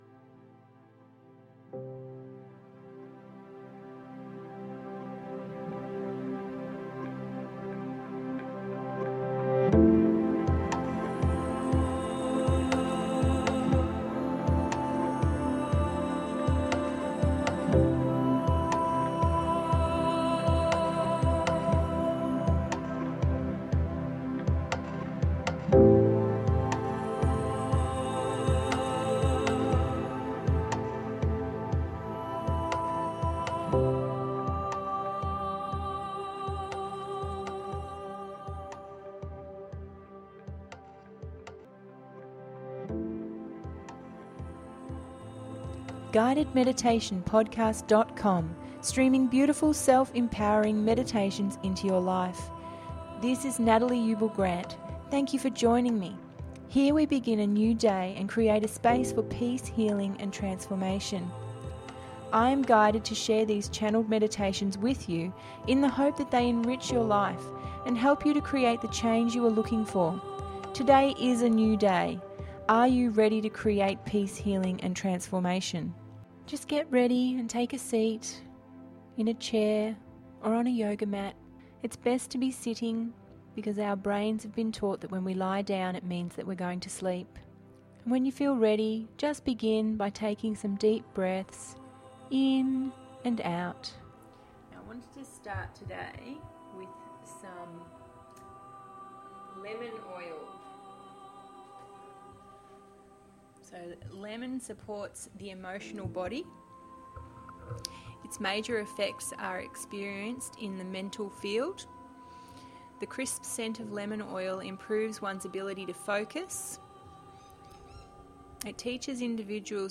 Dandelion Fields…050 – GUIDED MEDITATION PODCAST